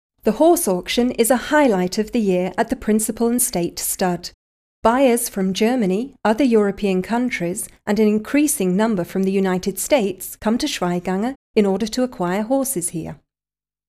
sprecherdemos
englisch w_03